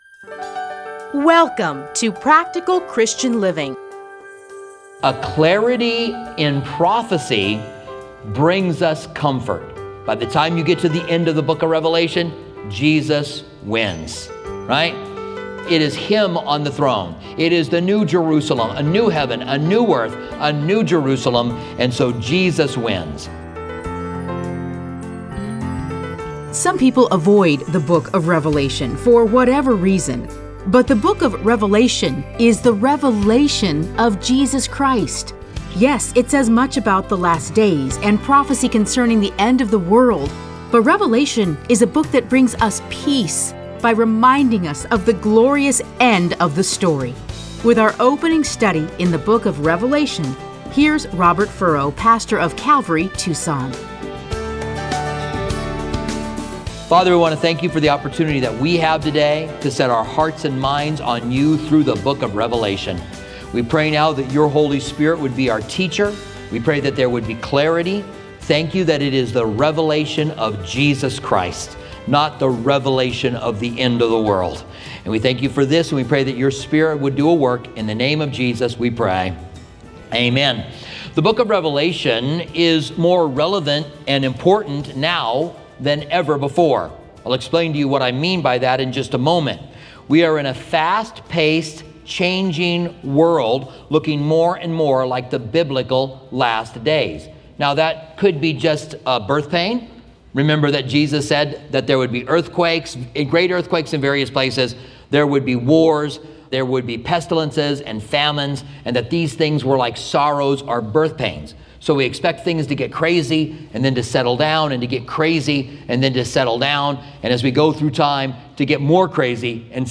Listen to a teaching from Revelation 1:1-3.